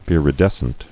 [vir·i·des·cent]